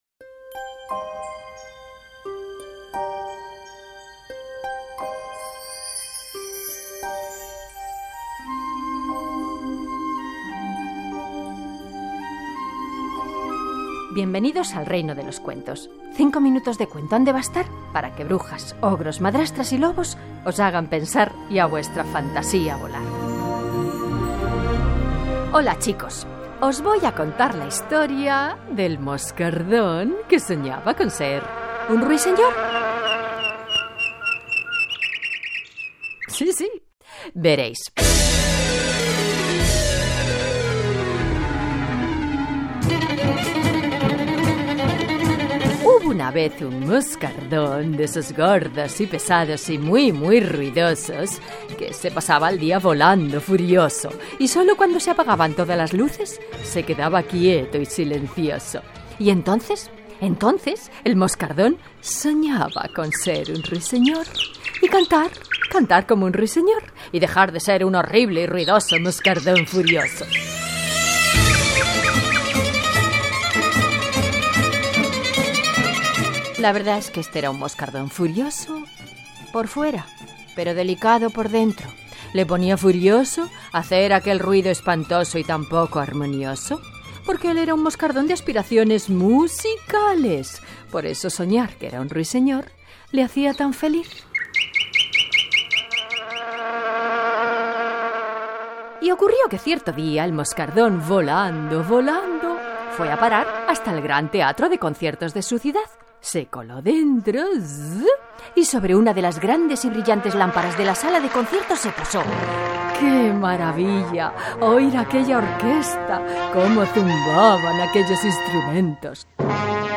Era imprescindible, para nosotras grabar un cuento y ofrecerlo en este apartado, esperamos que os guste, lo hemos realizado con mucha ilusión, hemos utilizado el Audacity  un  programa para la edición de audio con el cual puedes cortar, mezclar, agregar efectos, grabar y todo lo que se te ocurra para dar rienda suelta a tu creatividad en cuanto a música y sonidos se refiera.